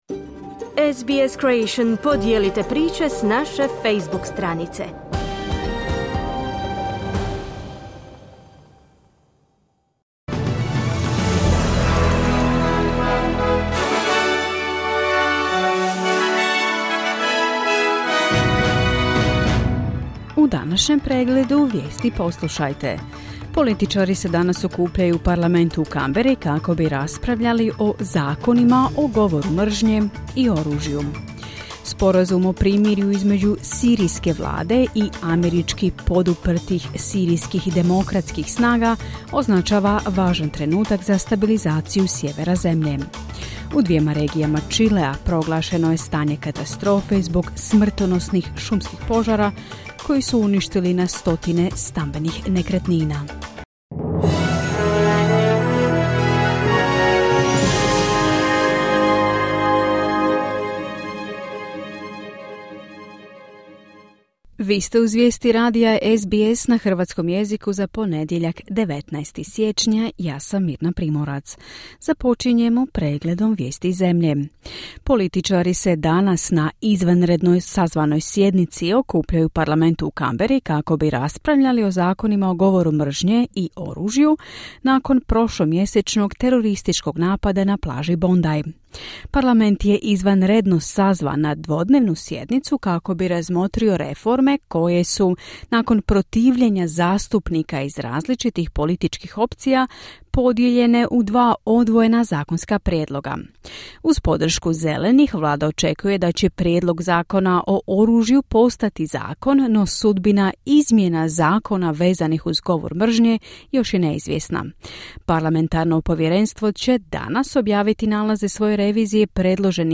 Vijesti radija SBS na hrvatskom jeziku.